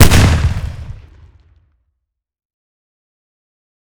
Decay/sound/weapons/arccw_ud/m1014/fire.ogg at 5c1ce5c4e269838d7f7c7d5a2b98015d2ace9247